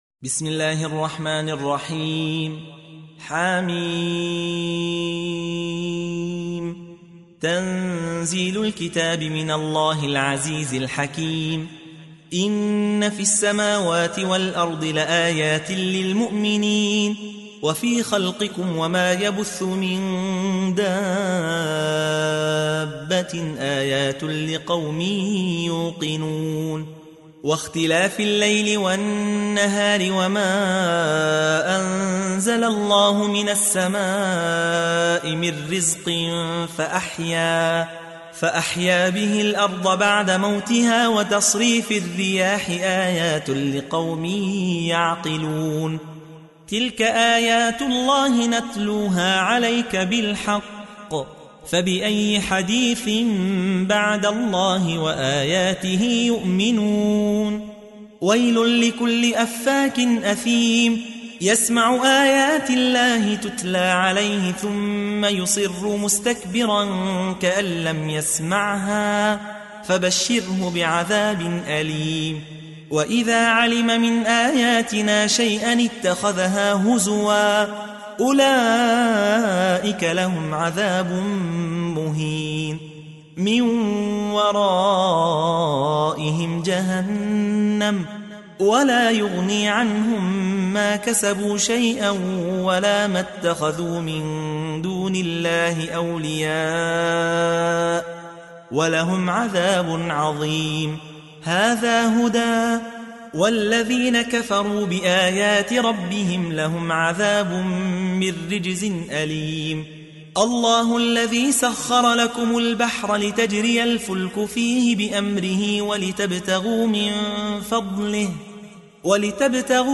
تحميل : 45. سورة الجاثية / القارئ يحيى حوا / القرآن الكريم / موقع يا حسين